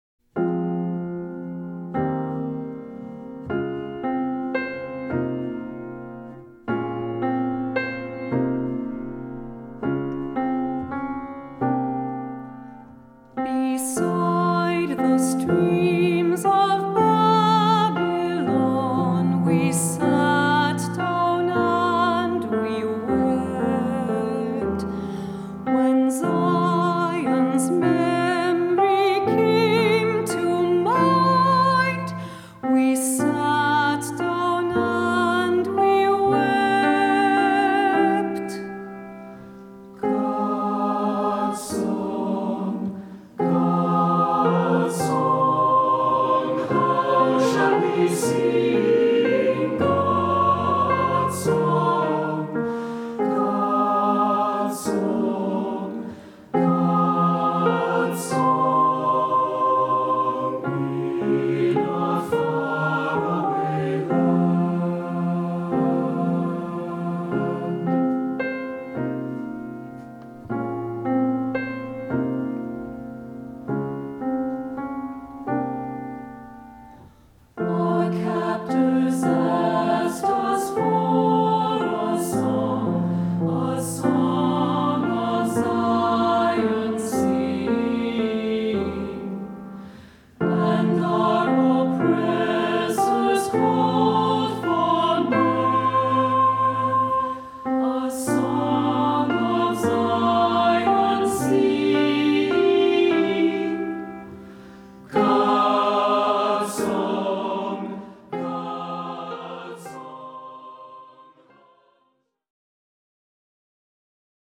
Voicing: SATB; Descant; Cantor